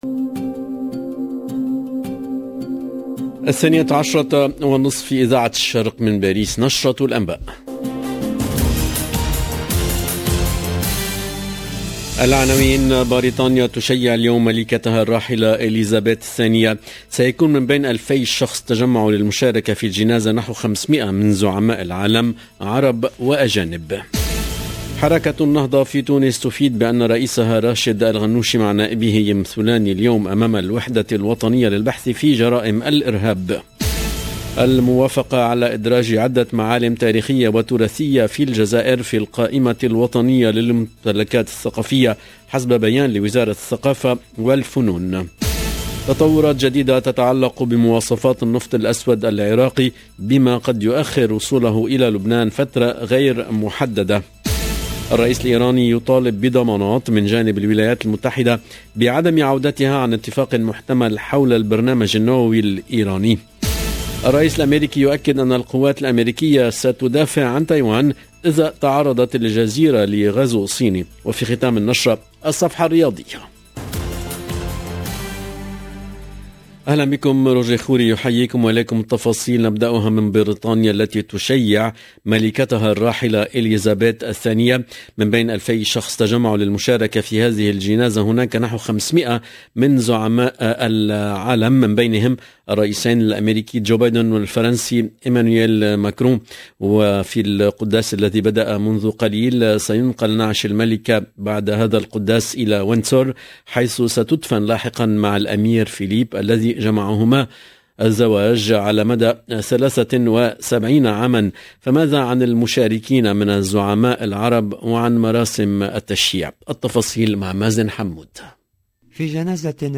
LE JOURNAL DE MIDI 30 EN LANGUE ARABE DU 19/09/22